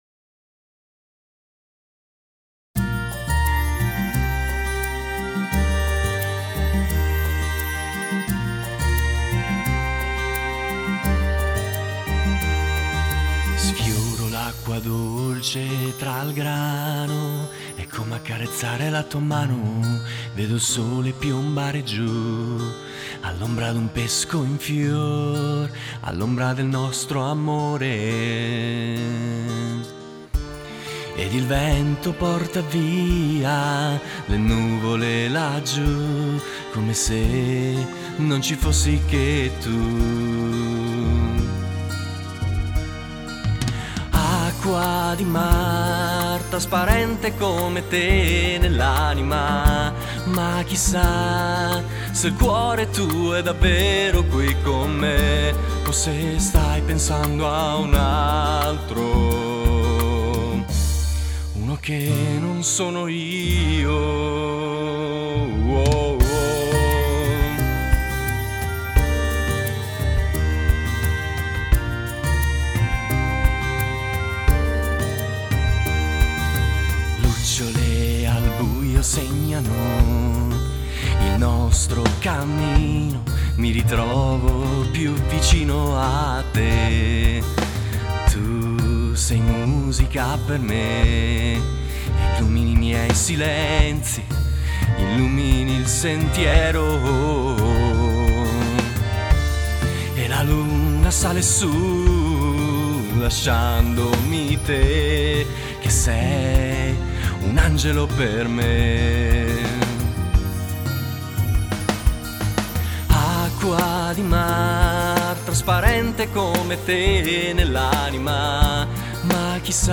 GenerePop / Musica Leggera